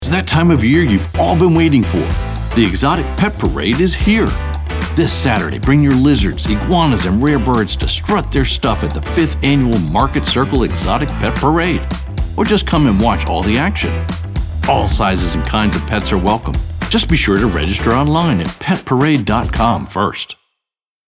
announcer, confident, friendly, genuine, high-energy, perky, upbeat